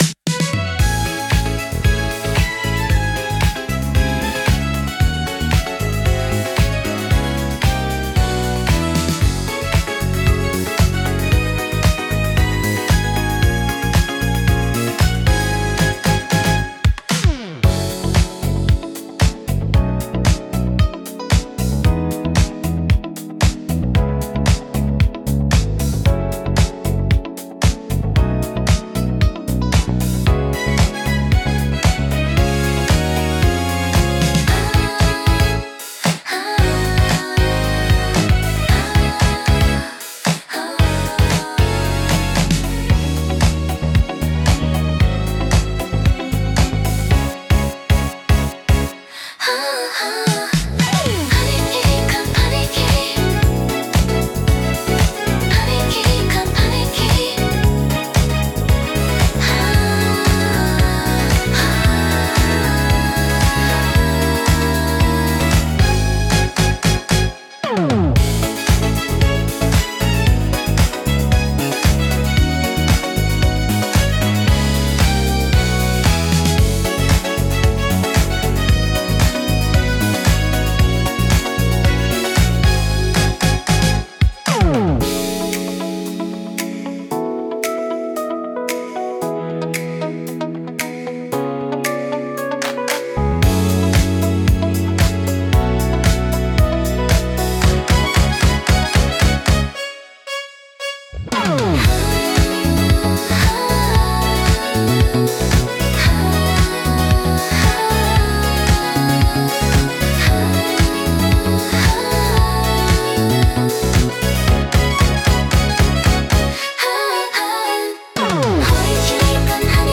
シティポップは、1970～80年代の日本で生まれたポップスの一ジャンルで、都会的で洗練されたサウンドが特徴です。